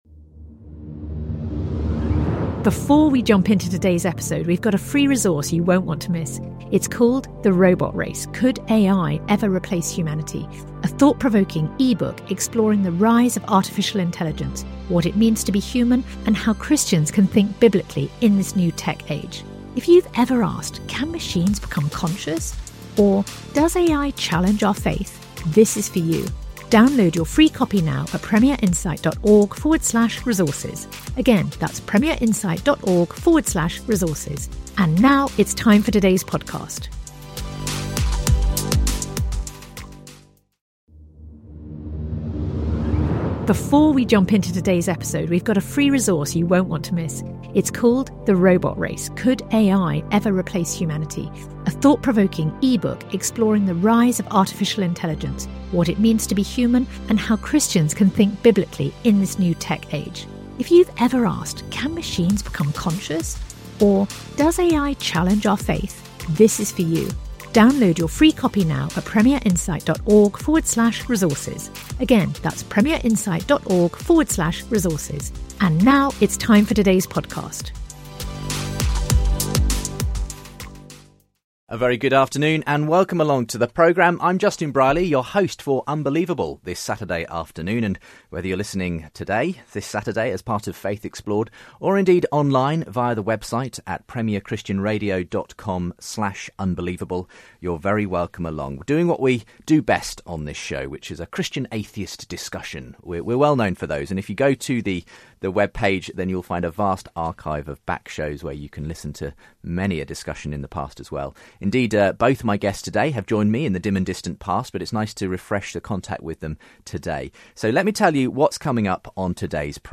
Debating the history of atheism